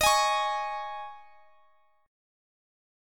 D#7sus4 Chord
Listen to D#7sus4 strummed